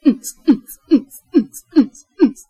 声乐 " PARODY 童话故事测试录音
用Zoom H2录制的。
标签： 例如 语音 英语 黑色 幽默
声道立体声